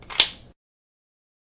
枪支重装的环境2
描述：简单重装M9。立体声和相当宽敞的声音。用2个轱辘电容式麦克风录制的。
标签： 环绕 环境 FX 手枪 重装 幻灯片 声音 9毫米 武器
声道立体声